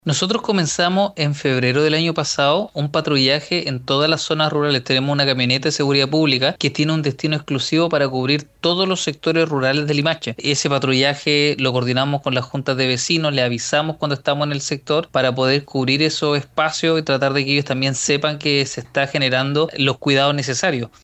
Por su parte, el alcalde de Limache, Luciano Valenzuela, se refirió a la implementación, desde el año pasado, de patrullajes en camionetas de seguridad pública, los que cuentan con un recorrido específico para patrullar las zonas rurales de la comuna.